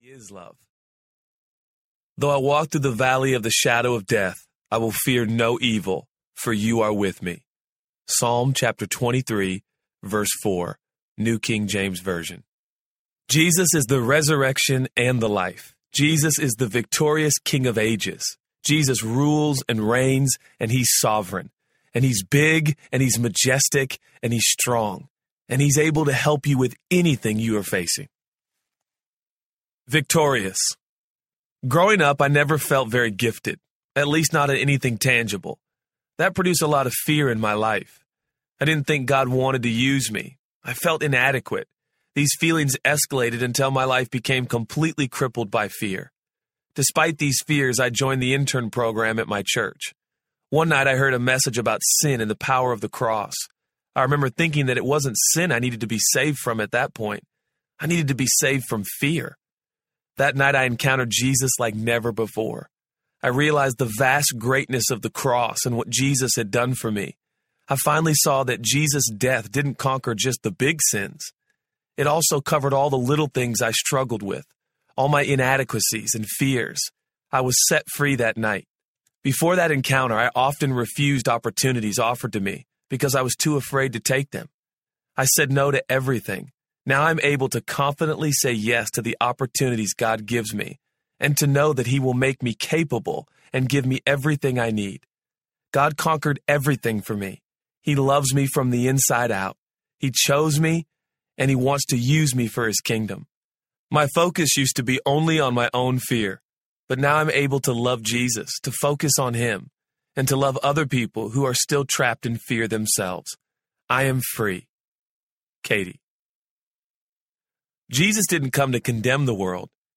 Jesus Is For You Audiobook
Narrator
1.18 Hrs. – Unabridged